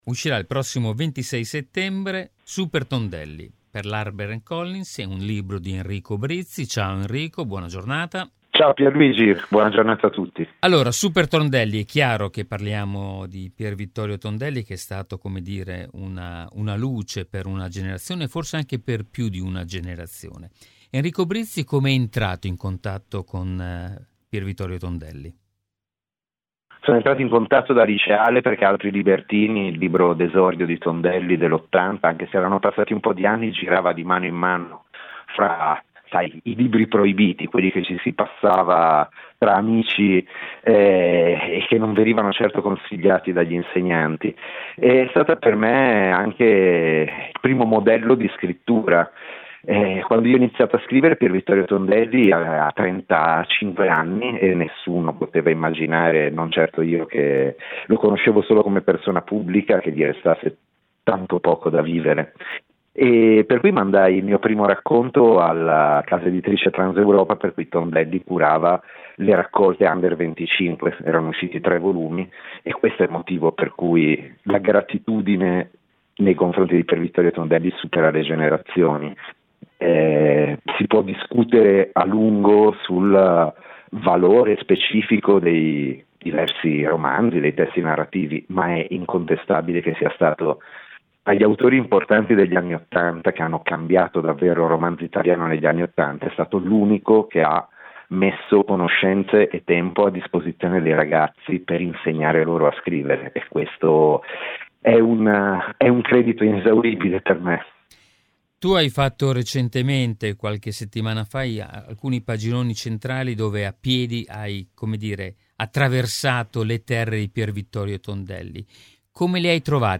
Home Magazine Interviste Enrico Brizzi presenta il suo nuovo libro “Supertondelli”